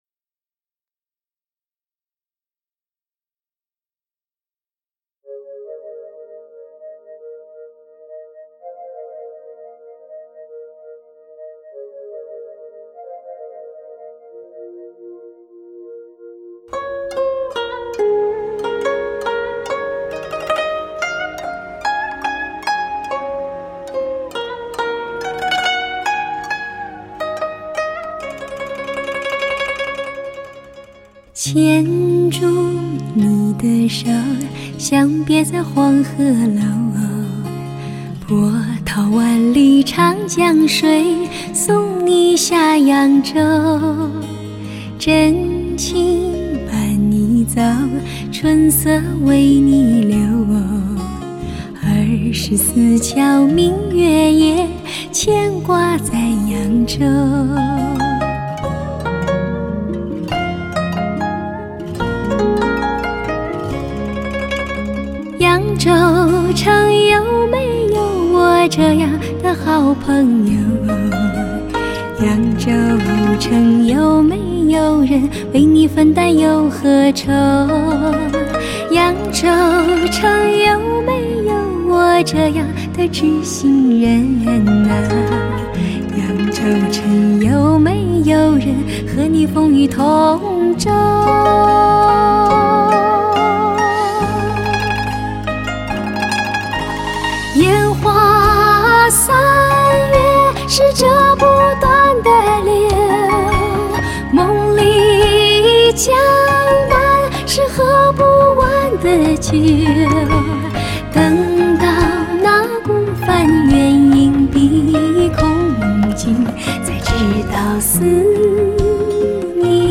细致的音像还原和准确的音场刻画，让人越听越舒服，入耳即溶。